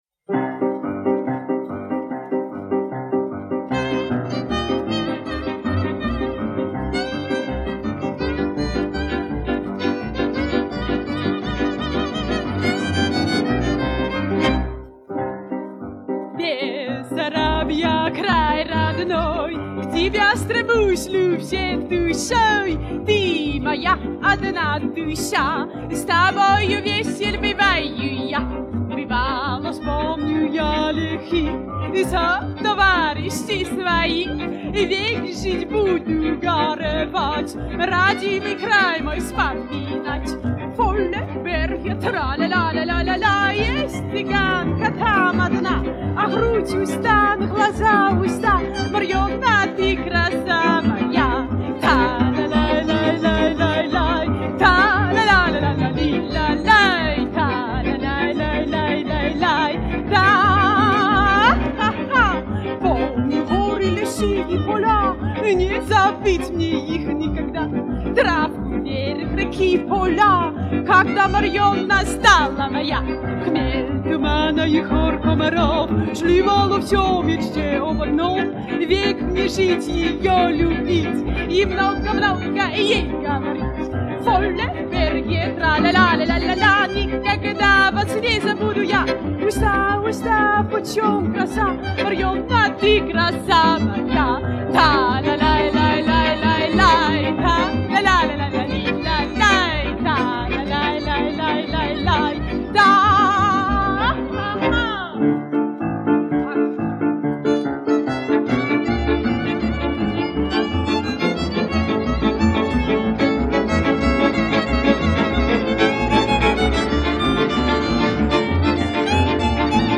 цыганского оркестра из Нидерландов